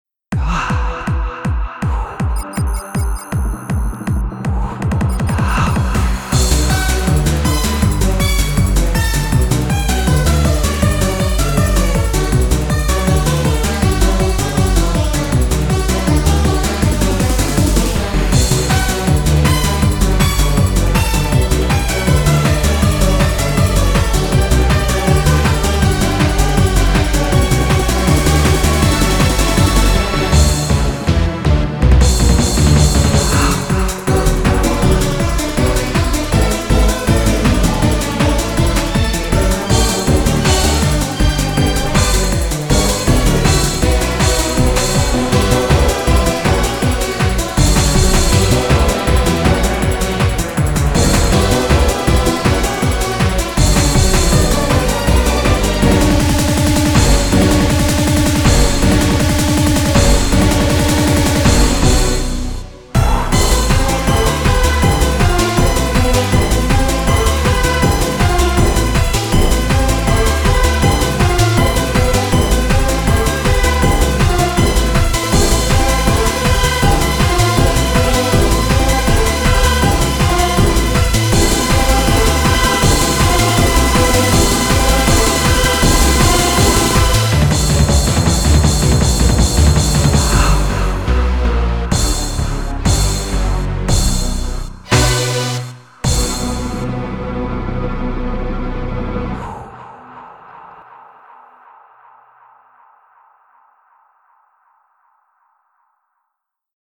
BPM53-160
Audio QualityPerfect (High Quality)
Genre: ROCOCO TEK.